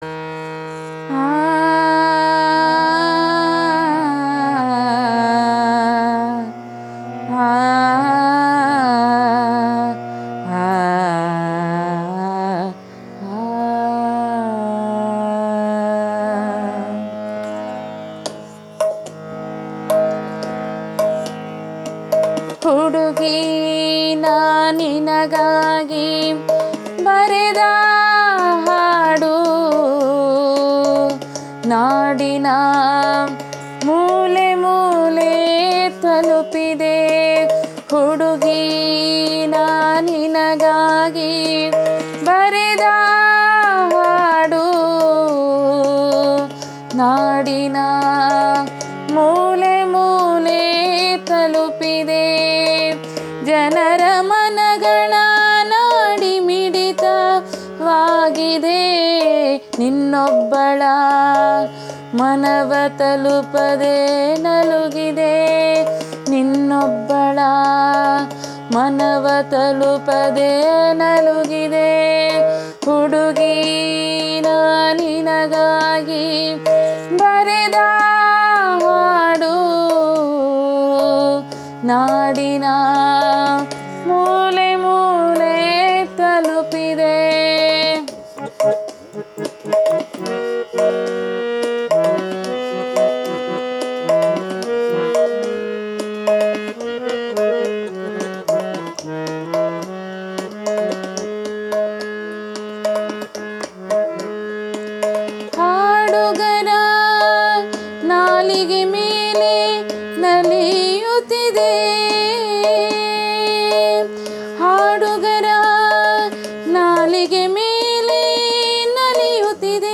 ರಾಗ ಸಂಯೋಜನೆ-ಗಾಯನ